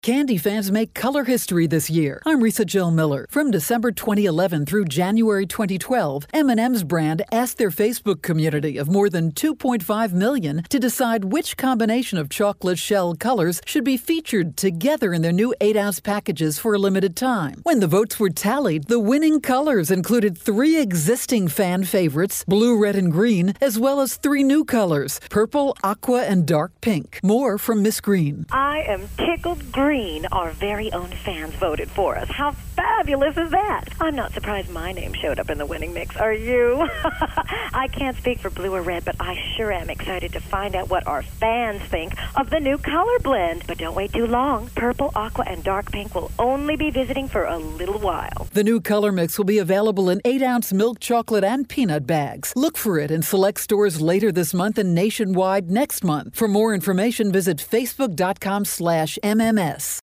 December 11, 2012Posted in: Audio News Release